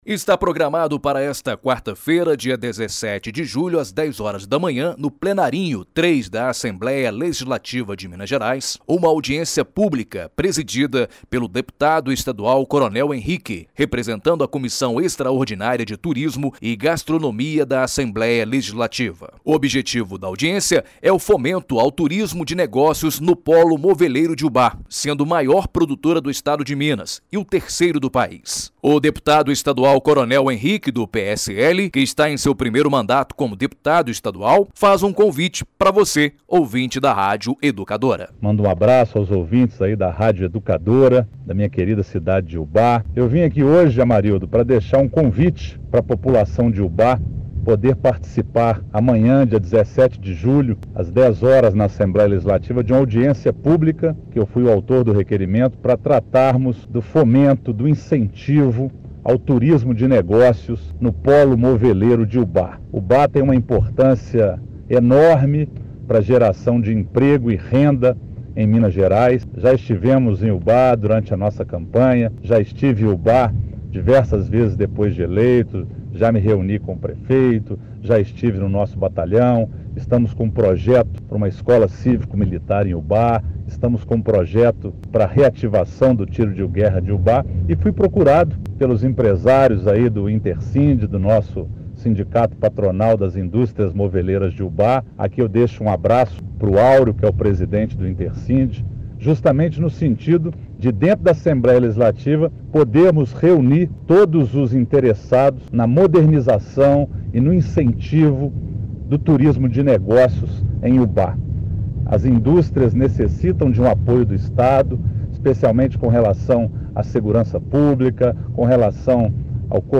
REPORTAGEM EXIBIDA NA RÁDIO EDUCADORA UBÁ – MG